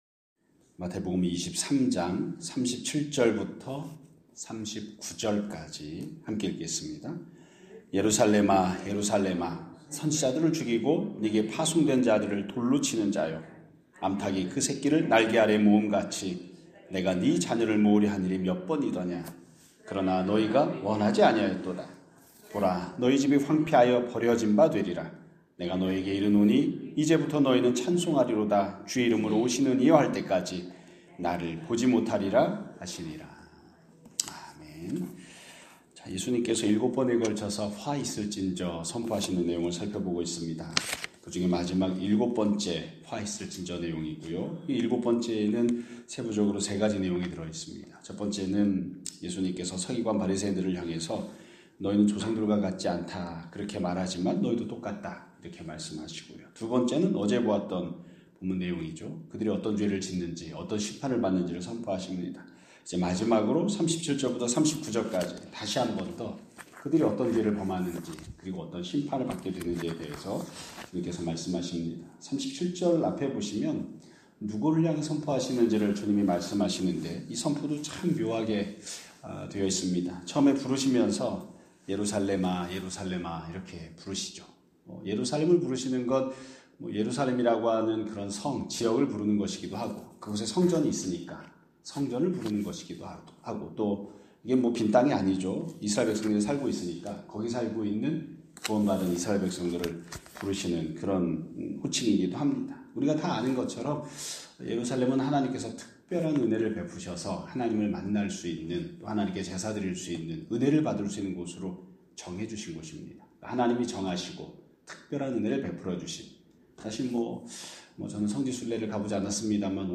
2026년 3월 4일 (수요일) <아침예배> 설교입니다.